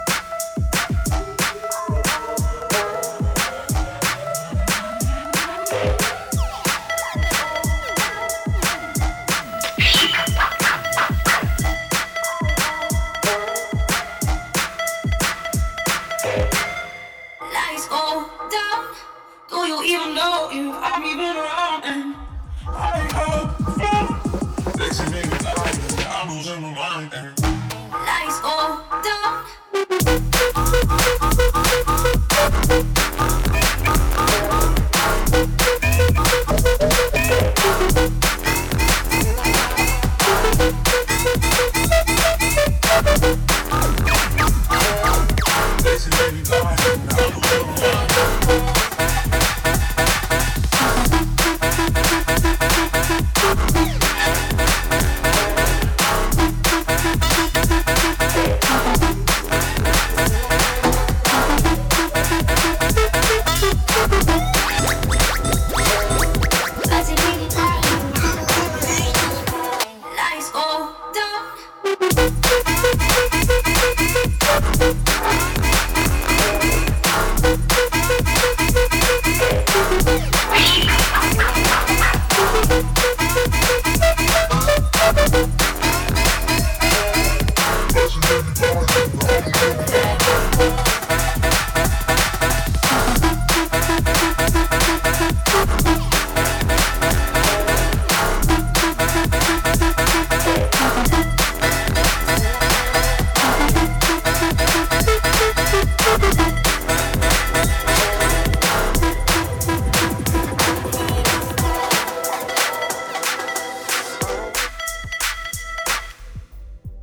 Got a really bouncy blend right here.